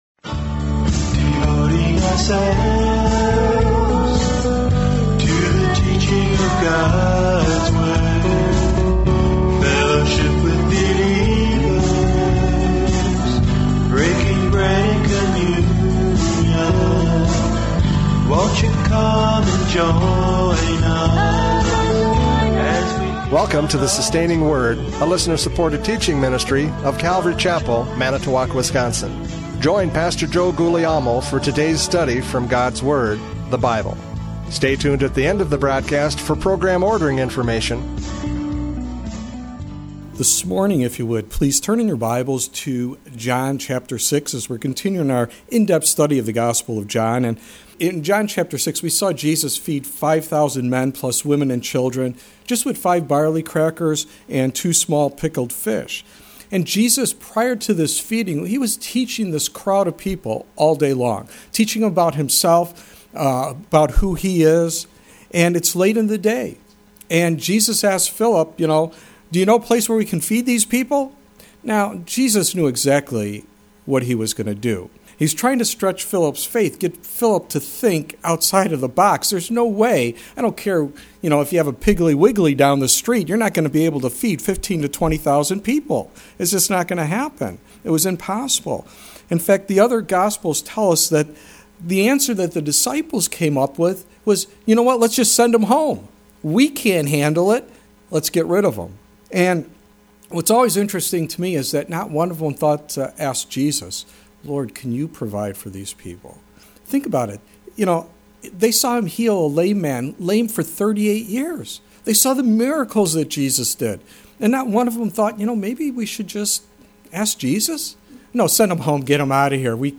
John 6:41-59 Service Type: Radio Programs « John 6:30-40 The Bread of Life!